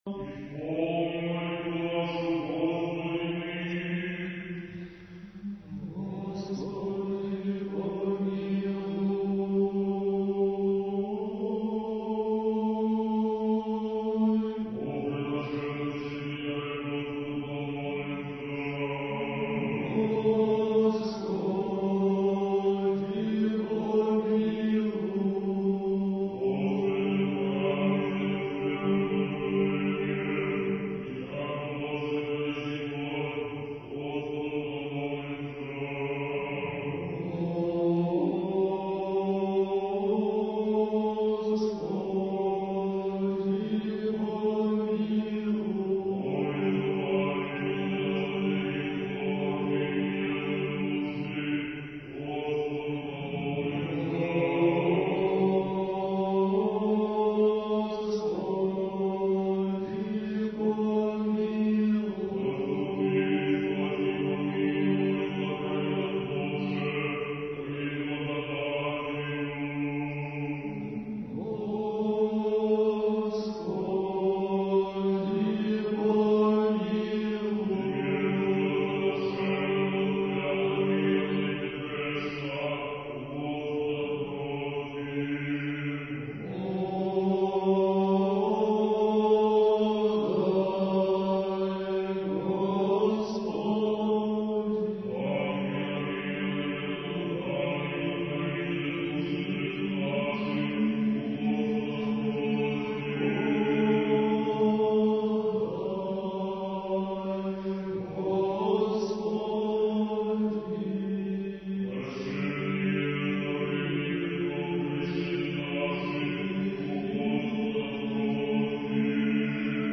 Архив mp3 / Духовная музыка / Русская / Хор Троице-Сергиевой Лавры под управлением архимандрита Матфея (Мормыля) / Литургия в Черниговско-Гефсиманском скиту /